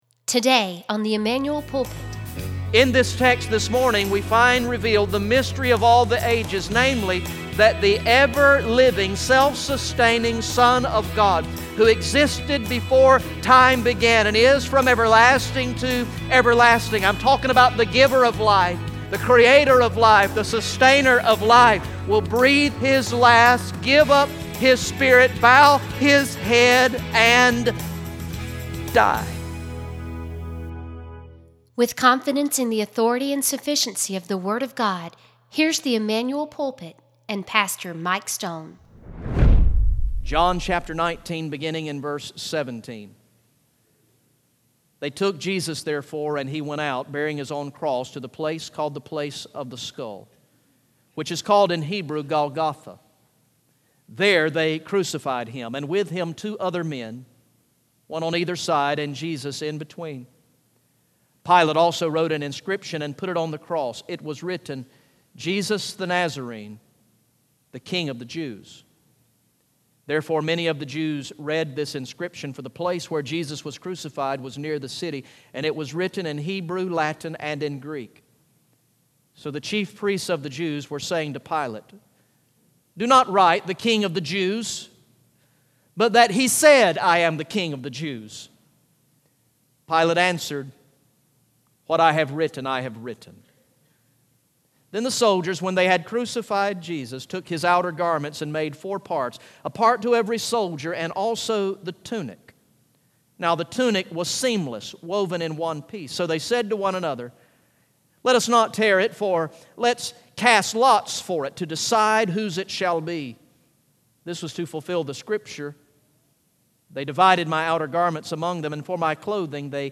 Message #71 from the sermon series through the gospel of John entitled "I Believe" Recorded in the morning worship service on Sunday, September 11, 2016